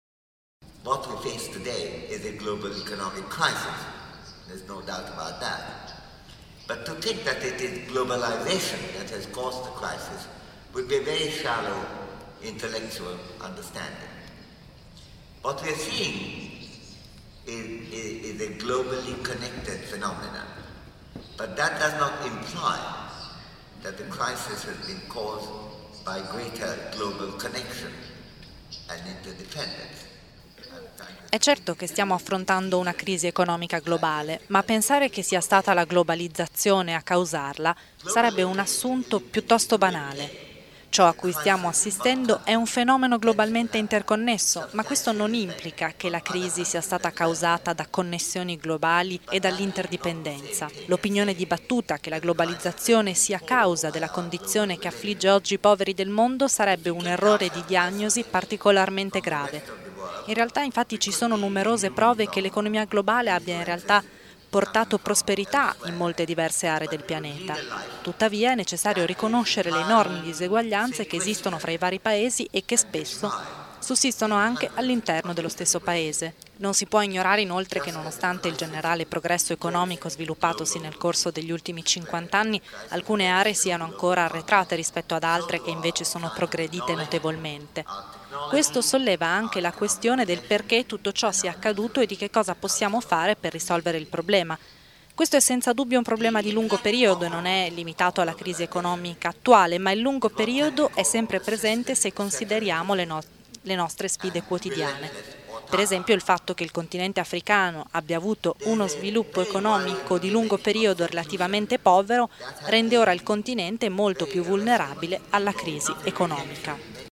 Amartya Sen, economista indiano e premio Nobel nel 1998, ha citato spesso il pensatore scozzese nella lectio magistralis che ha tenuto ieri mattina all’Oratorio di San Filippo Neri.
Ascolta l’intervento di Amartya Sen: